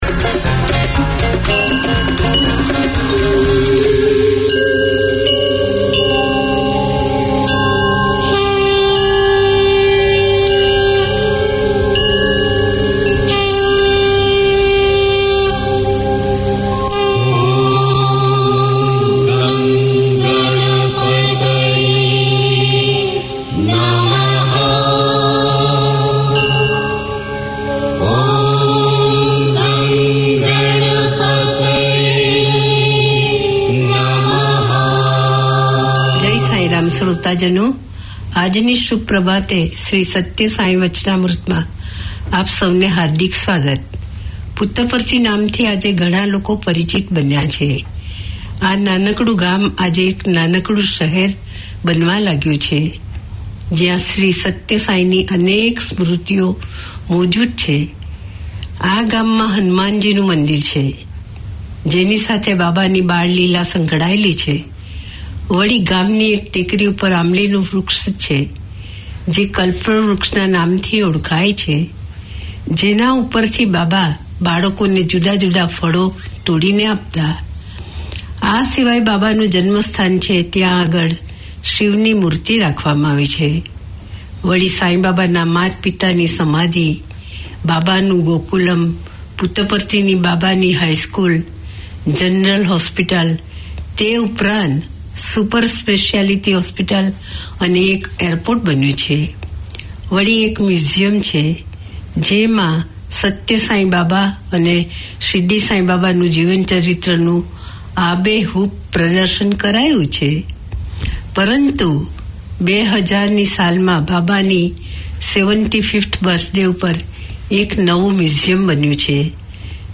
Each week Sai Baba devotees can pause for ten minutes to consider the teachings of Satya Sai Baba and hear devotional songs.